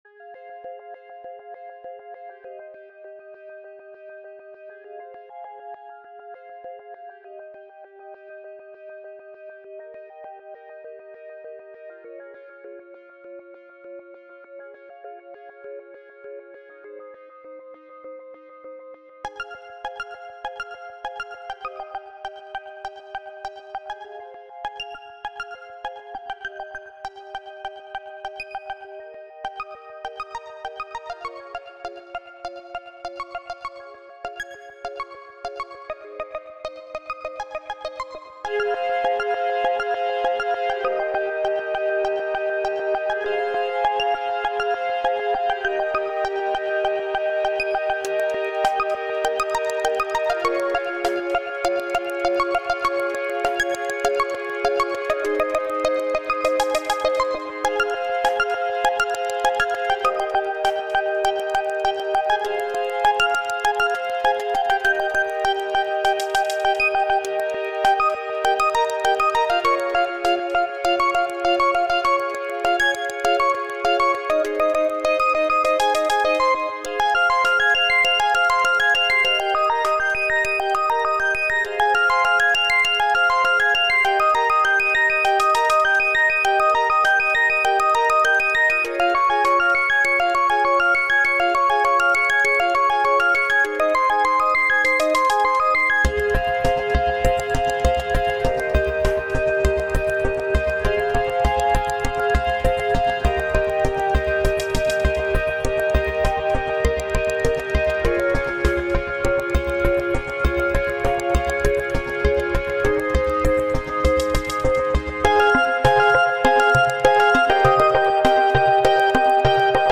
l’idea è realizzare un LP di musica elettronica